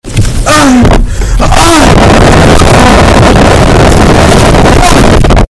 Sus Rage Bass Boosted Sound Effect Free Download
Sus Rage Bass Boosted